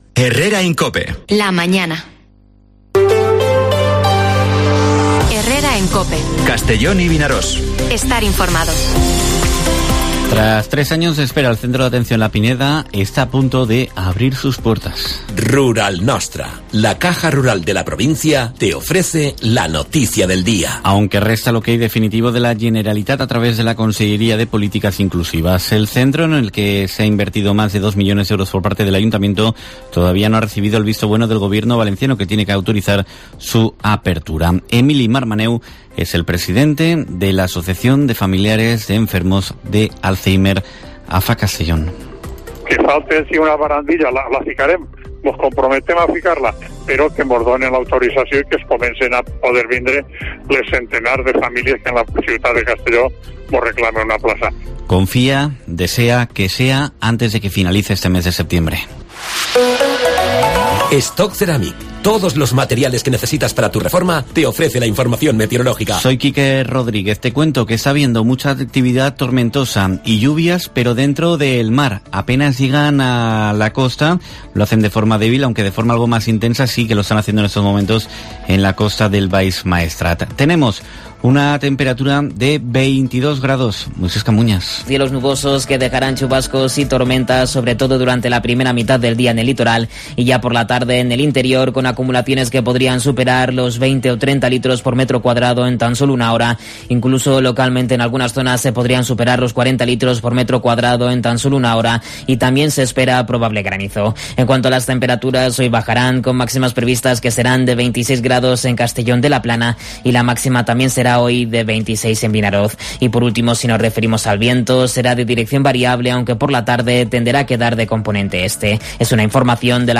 Informativo Herrera en COPE en la provincia de Castellón (22/09/2022)